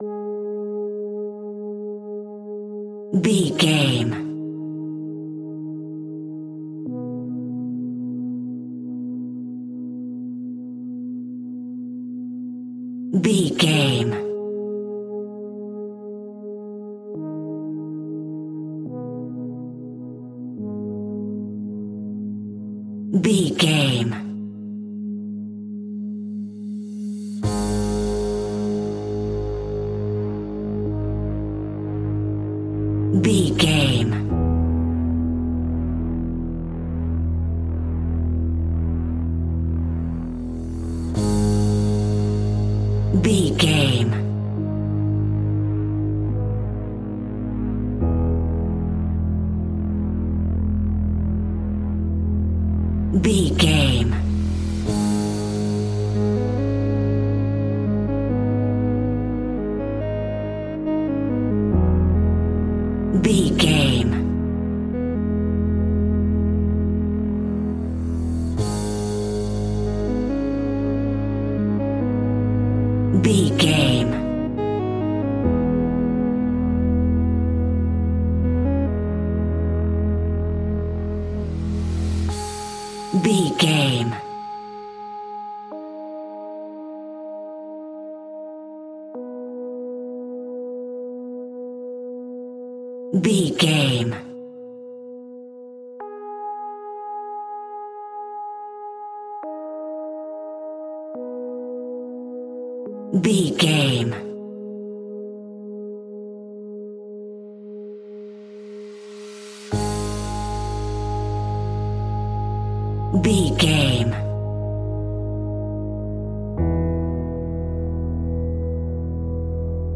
In-crescendo
Aeolian/Minor
G#
ominous
dark
haunting
eerie
piano
synthesiser
instrumentals
horror music